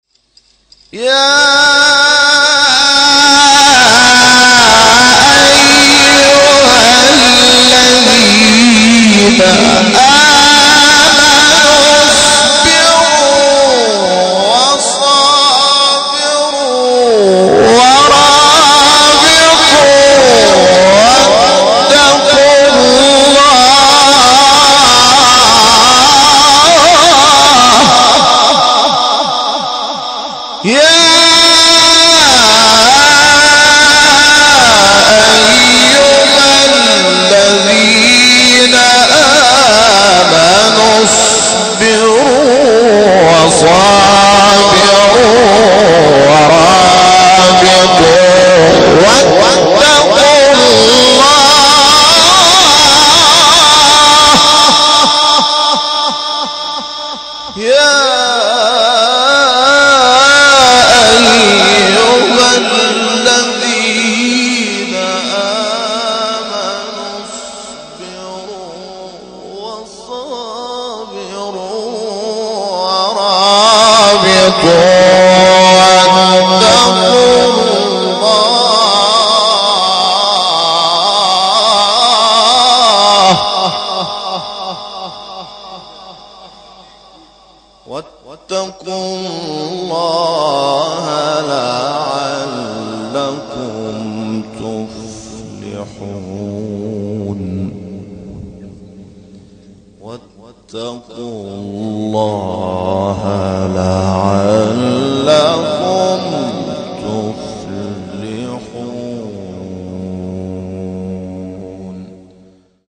سوره : آل عمران آیه : 200 استاد : محمود شحات مقام : بیات قبلی بعدی